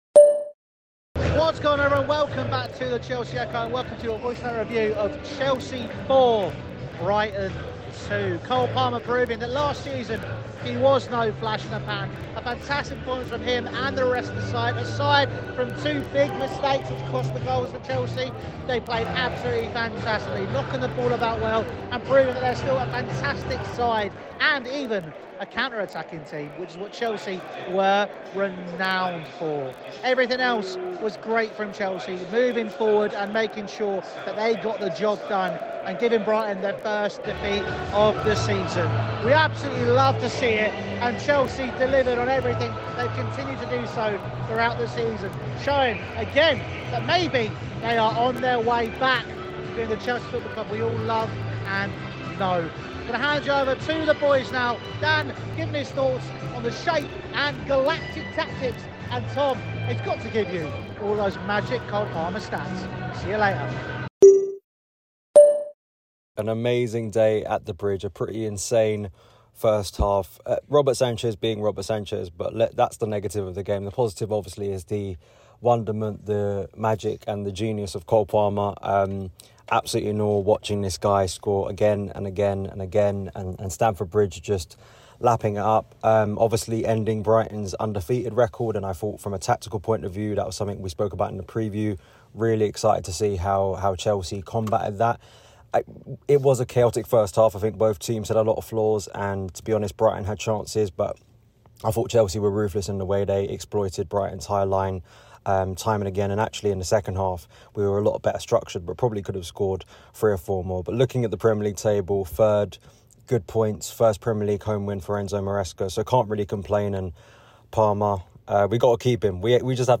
| Chelsea 4-2 Brighton | Voicenote Review
give you their thoughts straight after the game...